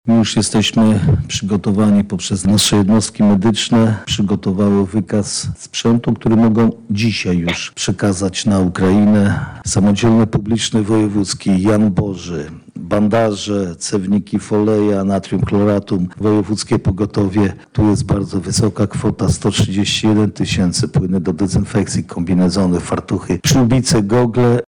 – mówi marszałek województwa lubelskiego Jarosław Stawiarski.